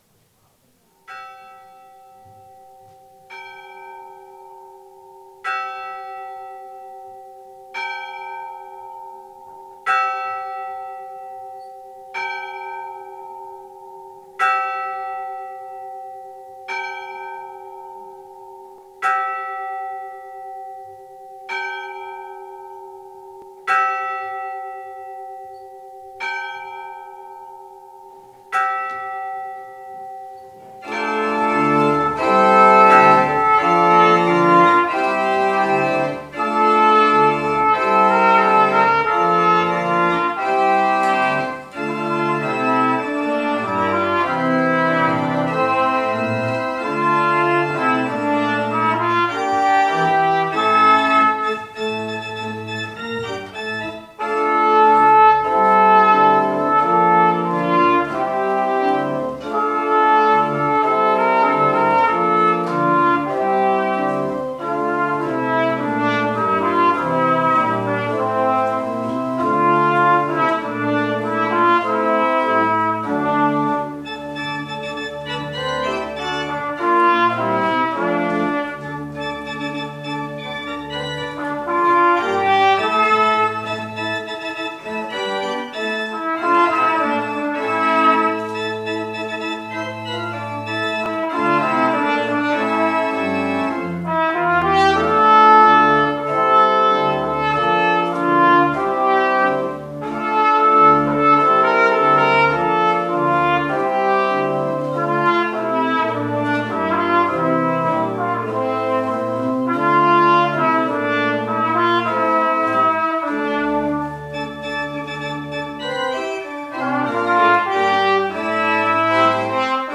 Jumalateenistus 5. aprill 2026